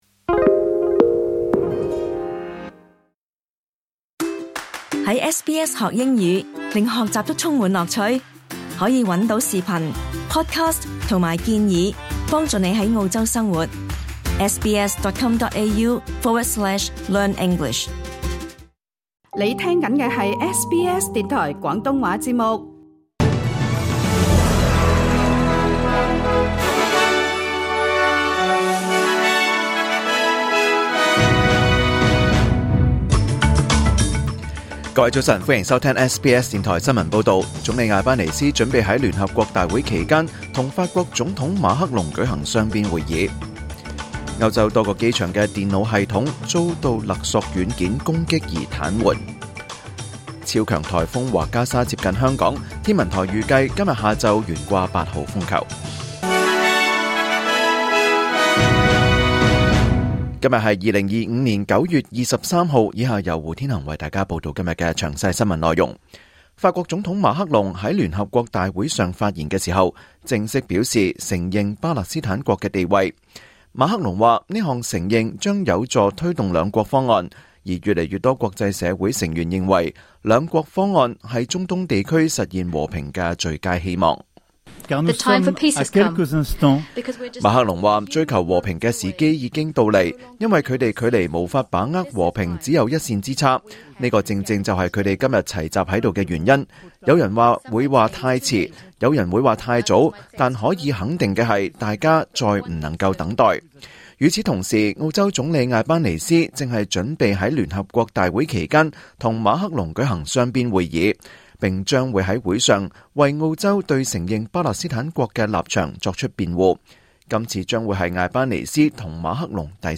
2025年9月23日SBS廣東話節目九點半新聞報道。